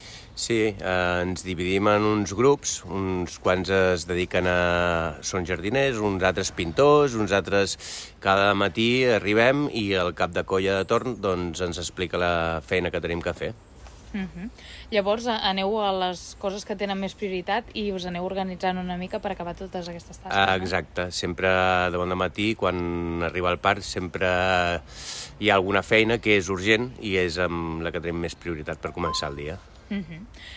Ho explica un dels treballadors de la brigada.